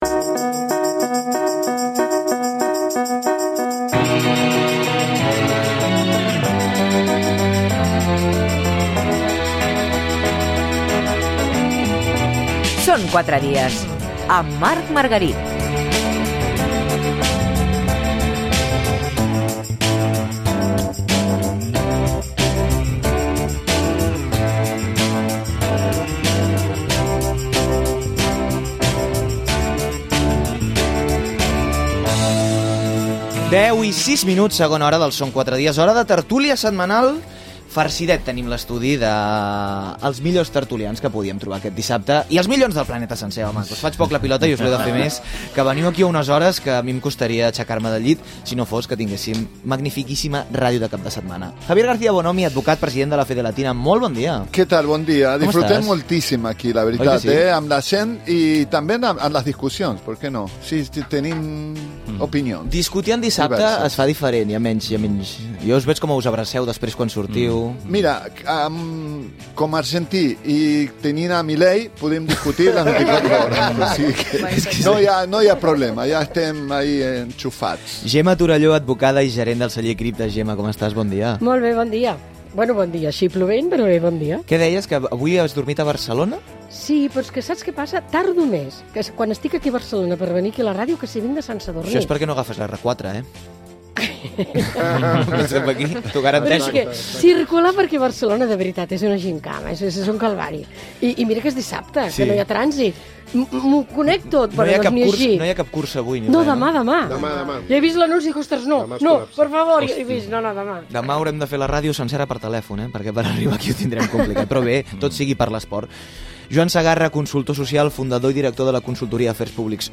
Tertúlia al Són 4 dies de Ràdio 4